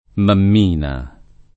[ mamm & na ]